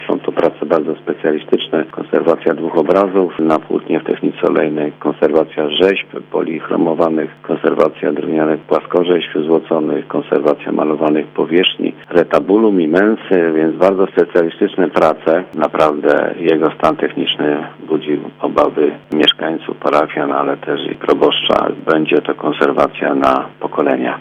Mówi wójt gminy Borowa, Stanisław Mieszkowski.